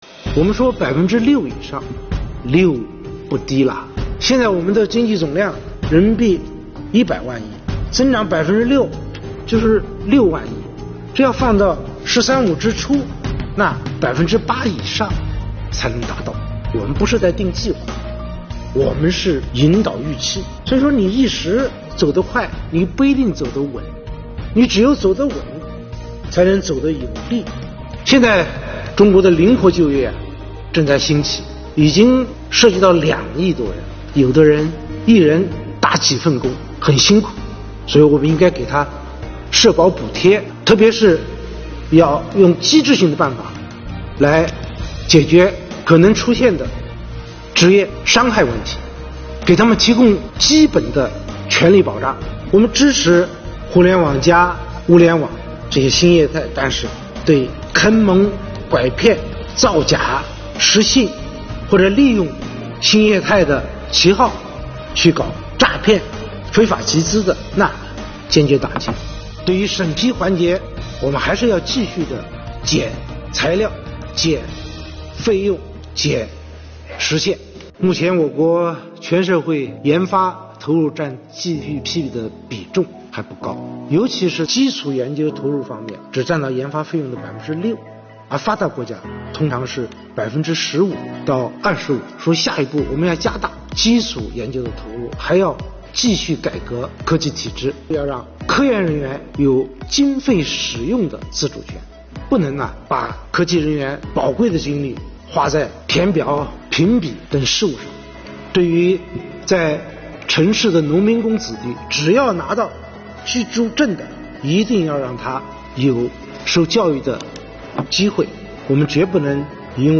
200秒速看总理记者会（精华版）